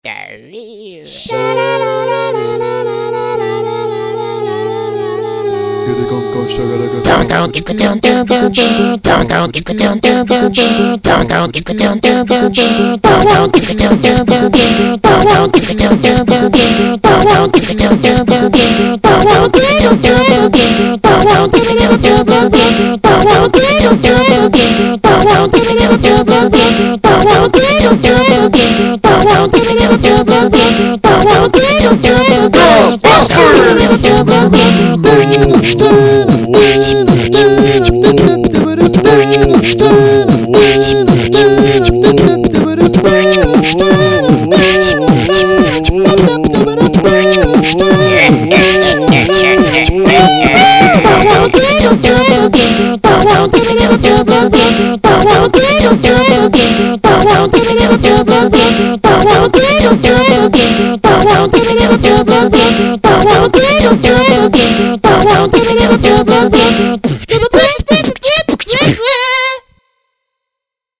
remix
beatbox